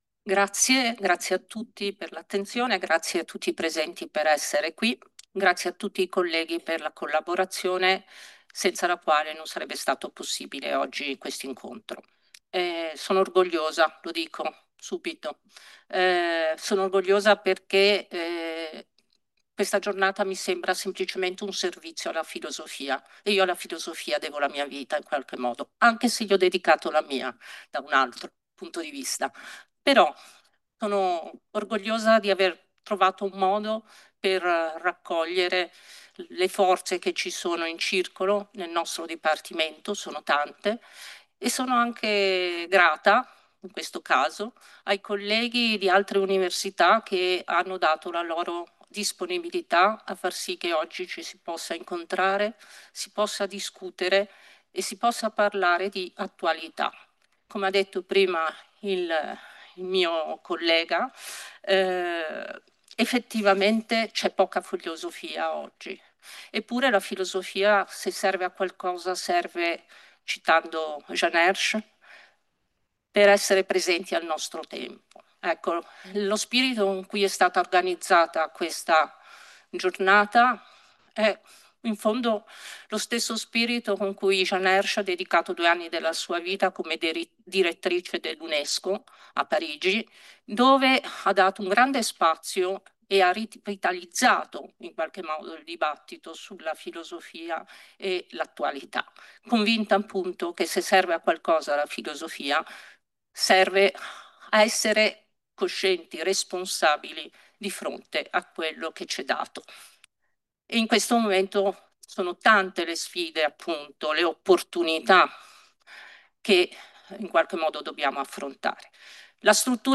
Registrazione integrale della conferenza “La Filosofia di fronte all’opportunità del futuro” per la Giornata Mondiale della Filosofia 2025, organizzata dall’Università di Genova e dall’Associazione Filosofica Ligure.